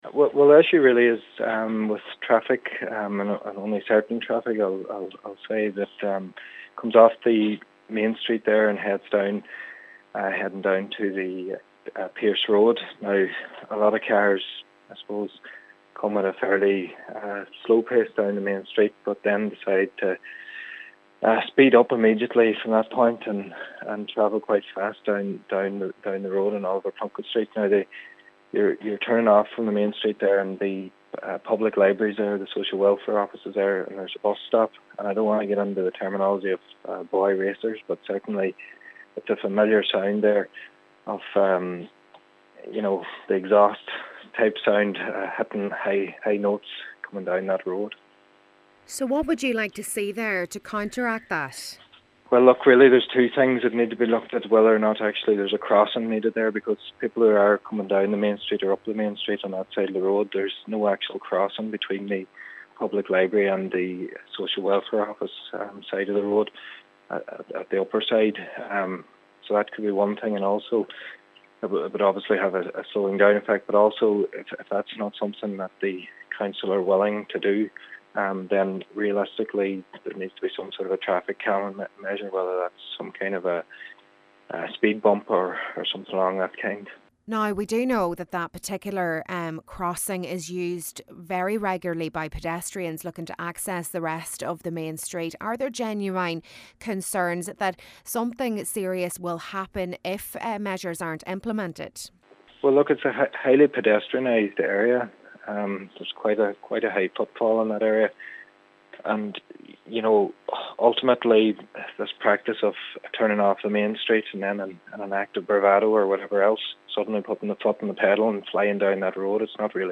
Local Cllr. Dessie Shiels says a number of options can be explored: